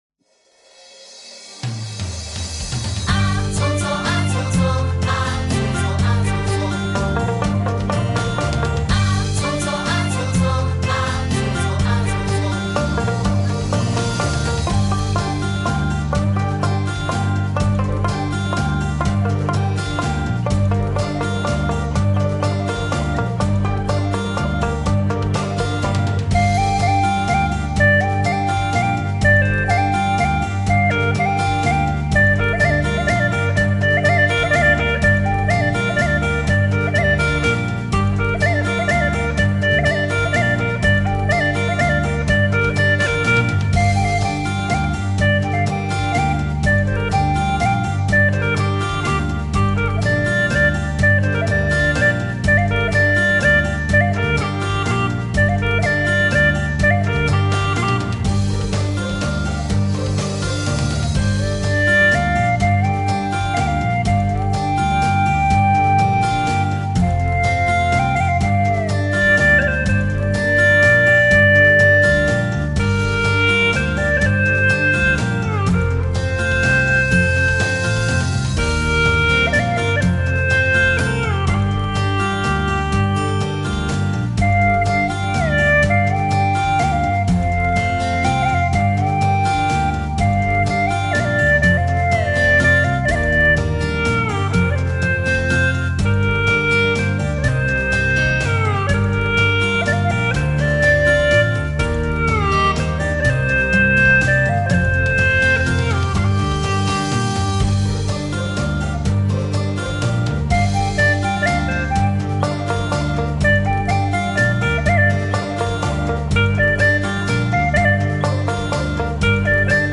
调式 : 降B 曲类 : 独奏